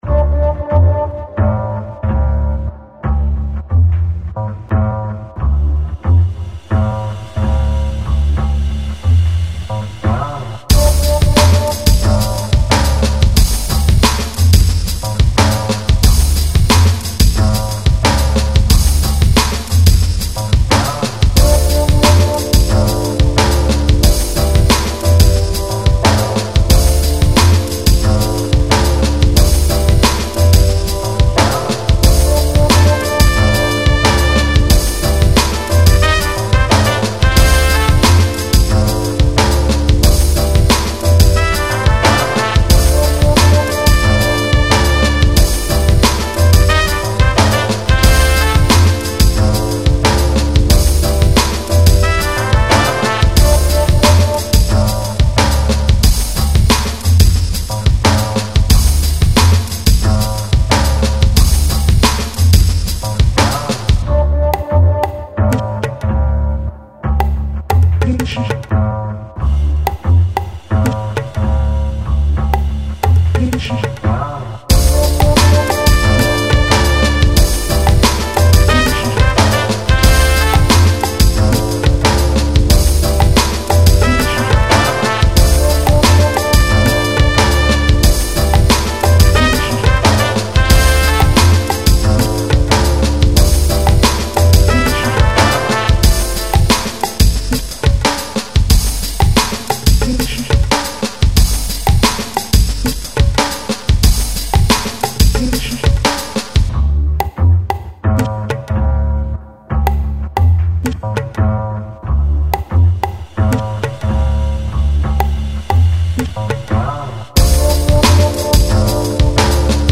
lounge - chill - voix - repetitif - gutare